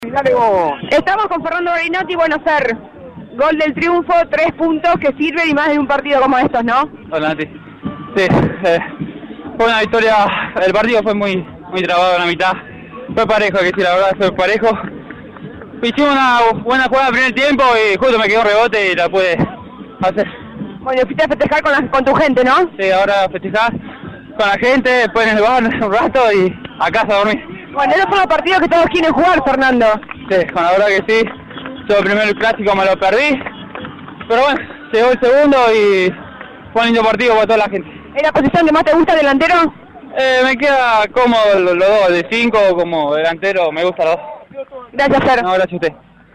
Fue transmisión de la radio